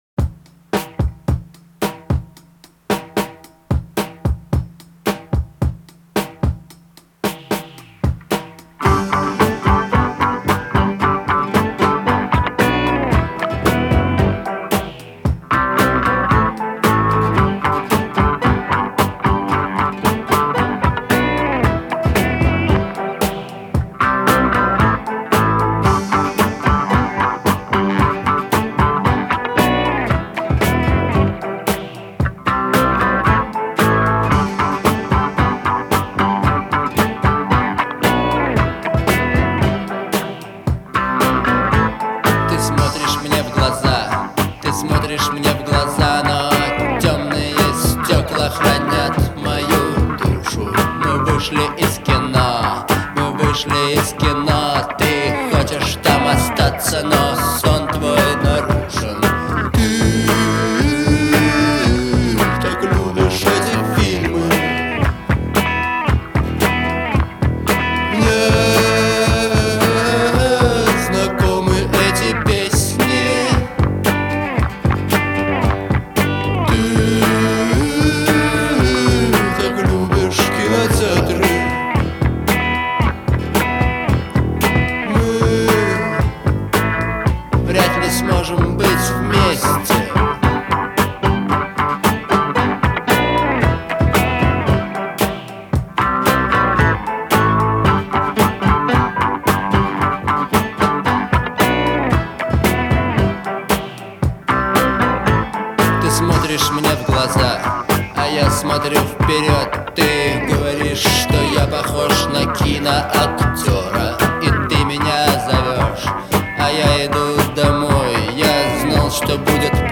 представляет собой мощное произведение в жанре рок.
мелодичный гитарный риф